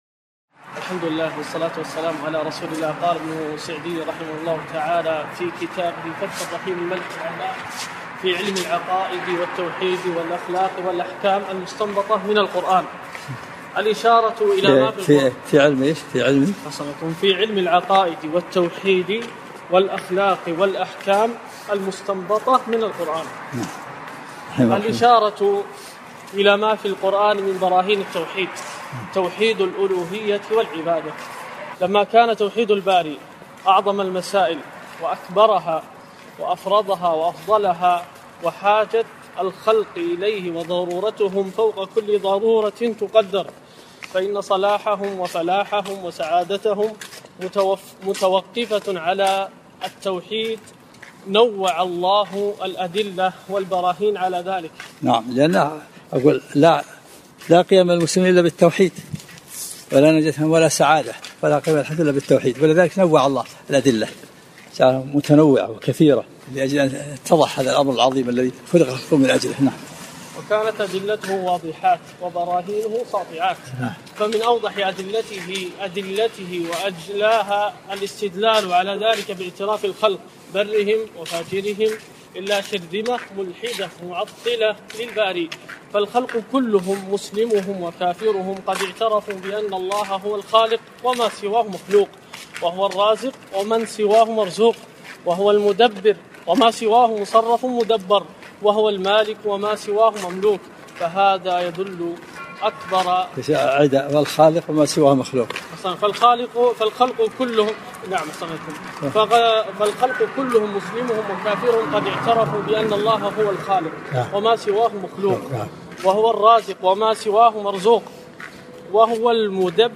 الدرس الثالث - فتح الرحيم الملك العلام في العقائد